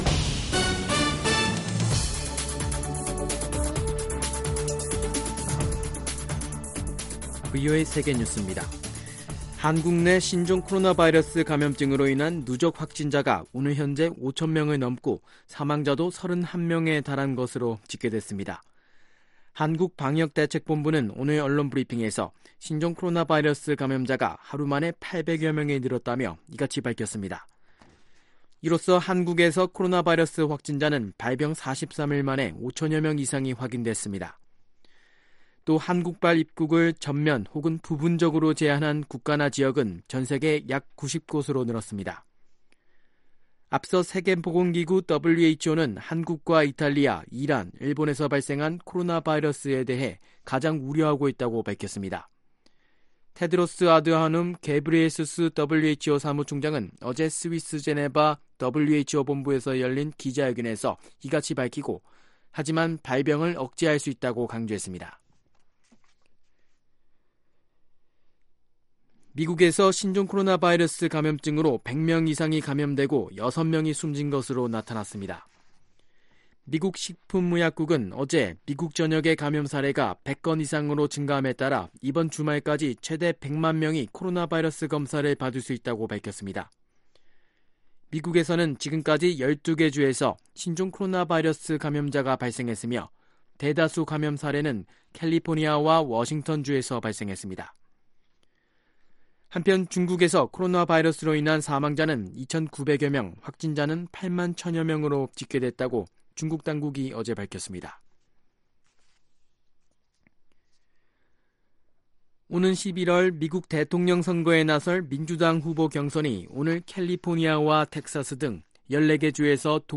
VOA 한국어 간판 뉴스 프로그램 '뉴스 투데이', 2019년 3월 3일 2부 방송입니다. 한국 정부는 신종 코로나바이러스 사태와 관련해 대북 방역물자 지원 문제를 관련기구들과 협의 중이라고 밝혔습니다. 유엔 안보리 3월 순회의장국인 중국이 교착 상태에 빠진 미-북 비핵화 협상과 관련해 유연성을 강조했습니다.